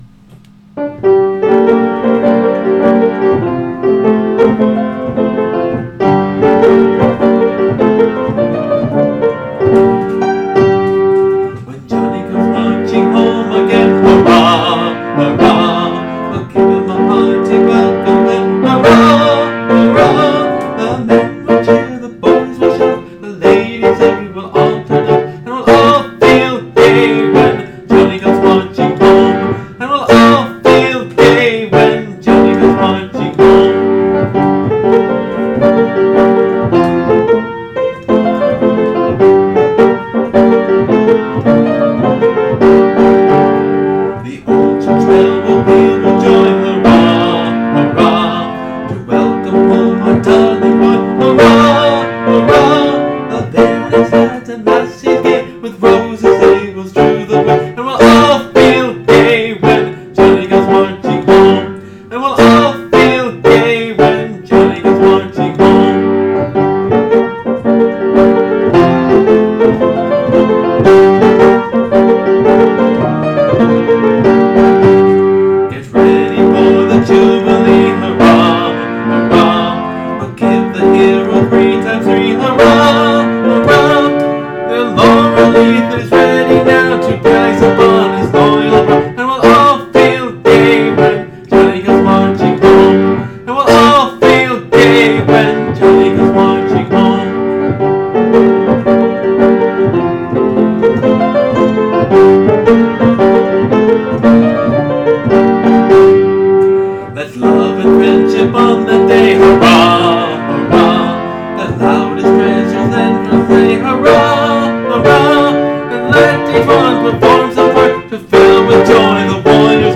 The accompaniment in the book shows how Celtic the tune is. I remember growing up on this one, and thinking of it as a Civil War song, which it is, but it was composed by a northerner, and I always thought of it as a southern song.
It is meant to be sung as solo line and then group, with the group doing the Hurrah’s and other ending phrases.